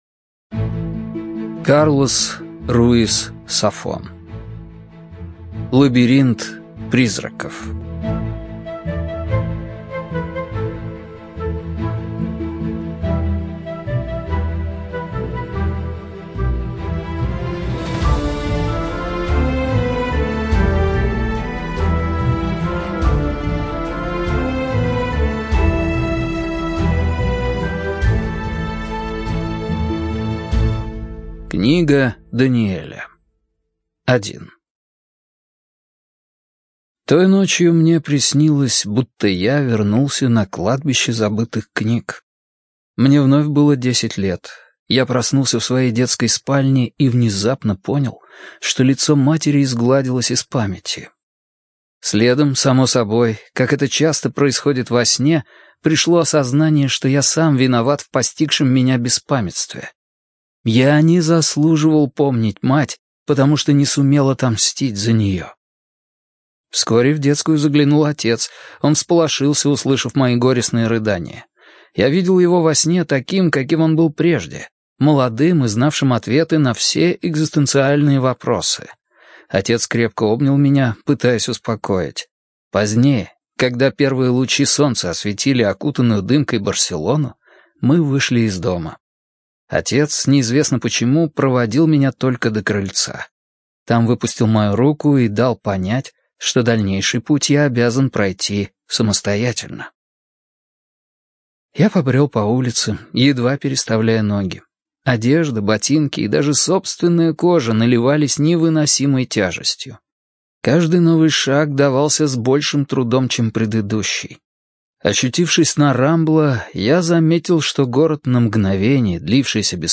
ЖанрУжасы и мистика